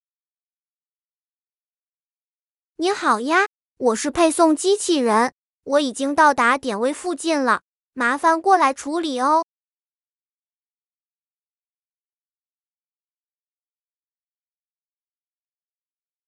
通知音响的音频文件支持自定义，默认使用了30%音量大小的播报语音，如果现场环境嘈杂，可以自行更新更大音量的音频。